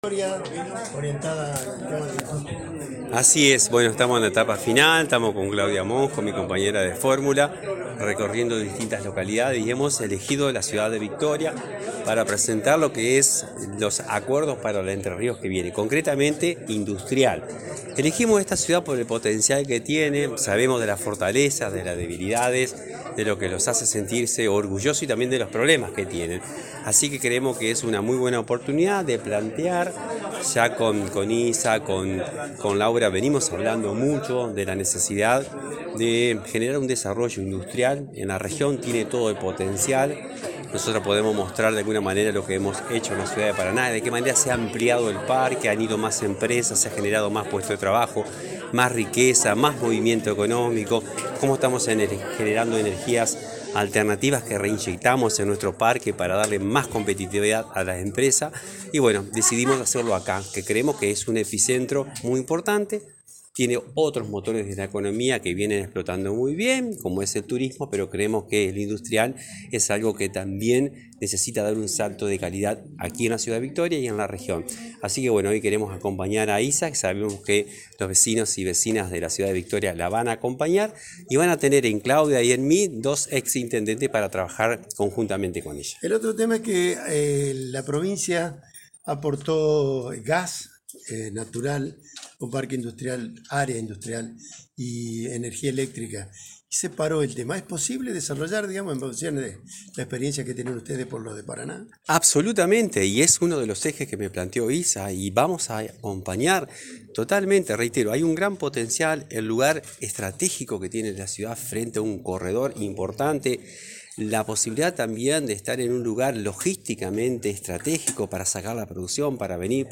Previo al inicio de la actividad, Bahl estuvo en diálogo con la prensa.